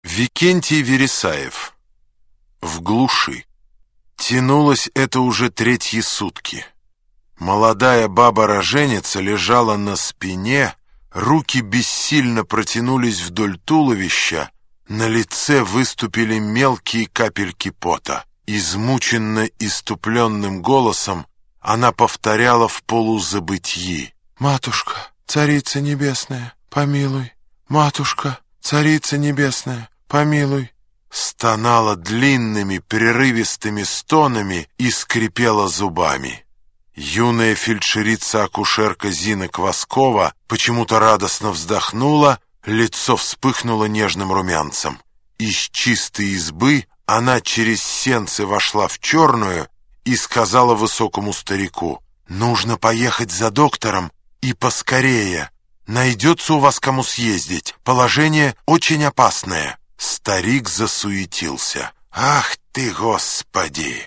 Аудиокнига Избранное | Библиотека аудиокниг